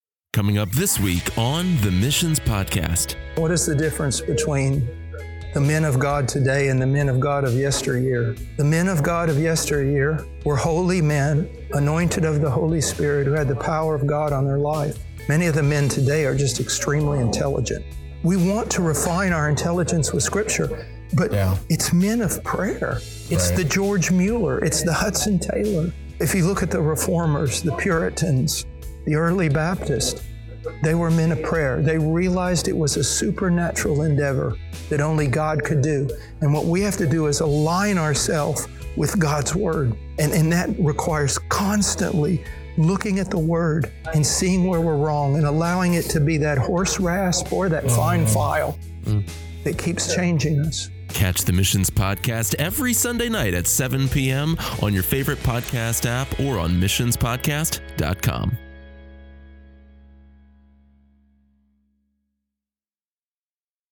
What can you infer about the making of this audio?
Missionary Conference in Jacksonville, Florida